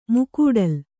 ♪ mukūdal